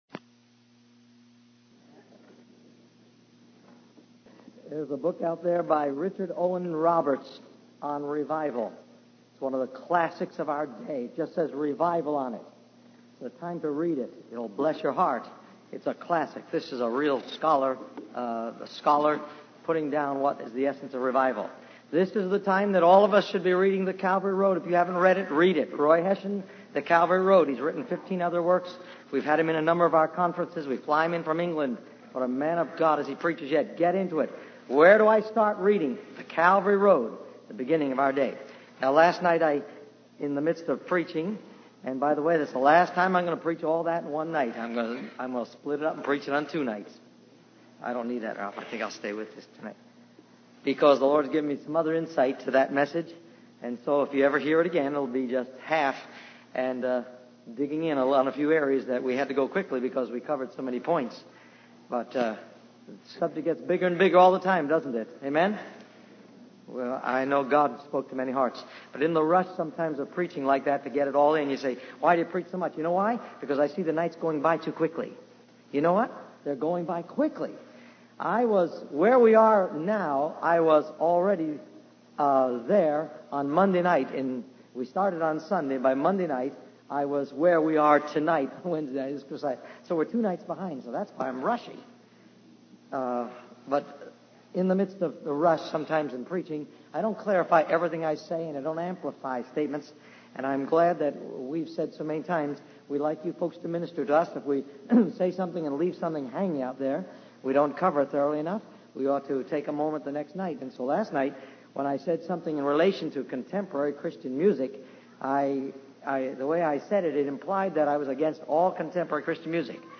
In this sermon, the preacher discusses the deceptive nature of pride and how it can hinder our relationship with God. He warns against lifting up novices or new believers too quickly, as it can lead to pride and ultimately their downfall. The preacher emphasizes the importance of humility and being broken before God.